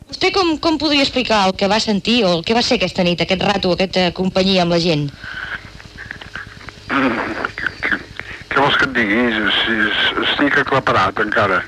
Homentage a Joan Oliver "Pere Quart" al teatre Romea.